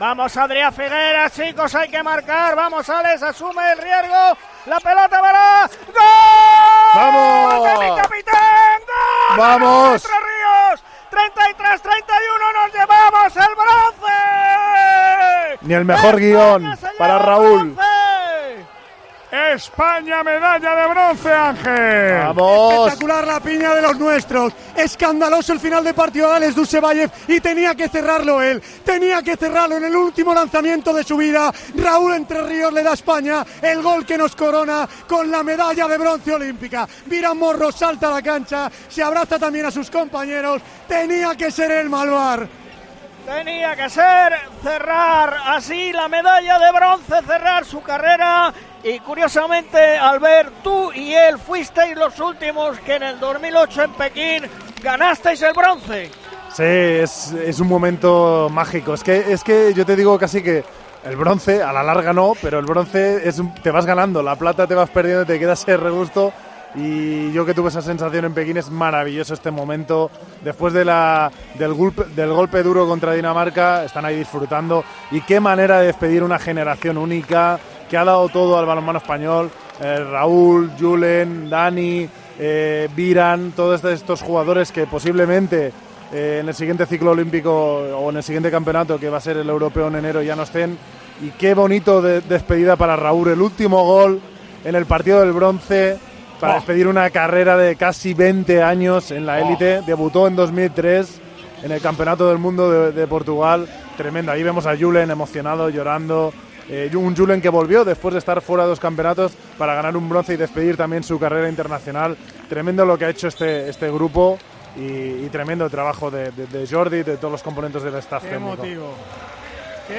A continuación puedes escuchar cómo hemos narrado en 'Tiempo de Juego', con nuestro equipo de enviados especiales, las 17 medallas que ha conseguido nuestro país en Tokio.